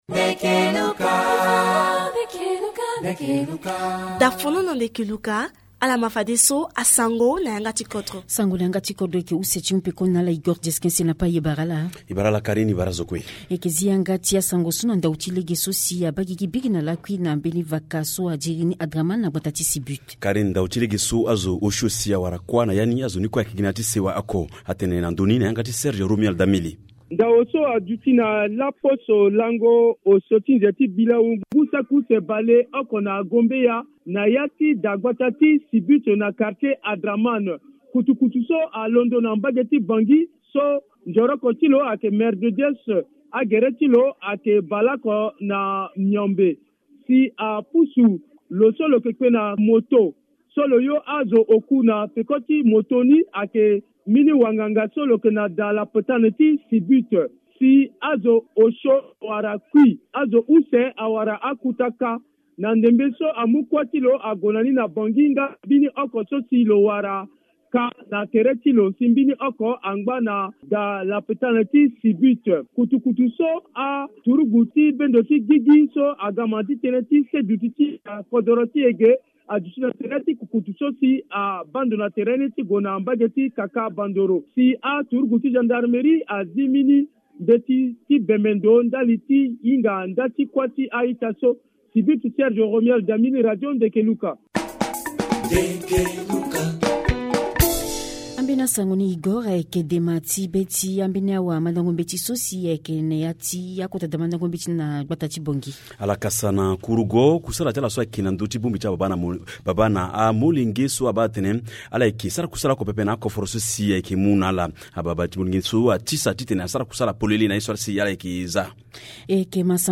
Journal Sango